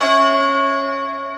Key-bell_94.1.1.wav